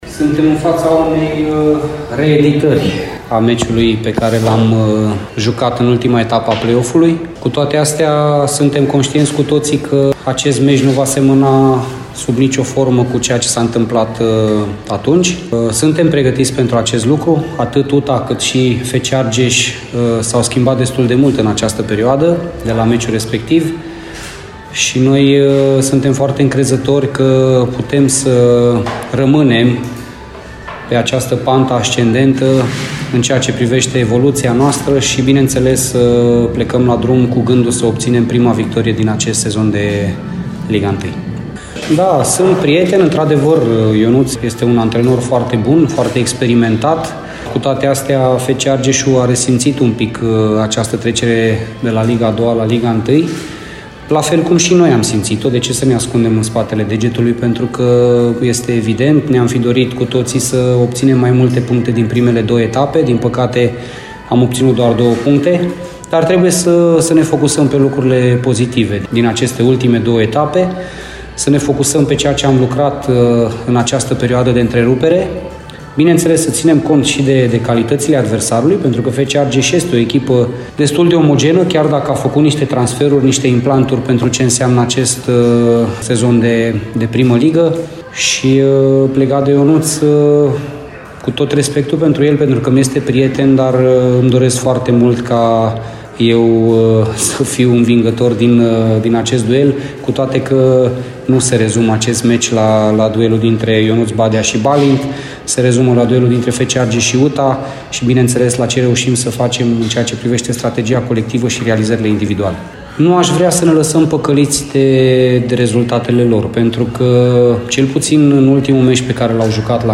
Antrenorul roș-albilor, Balint Laszlo, a vorbit despre partida de vineri și despre prietenia sa cu antrenorul oponenților, Ionuț Badea: